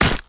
Sfx_creature_penguin_hop_land_09.ogg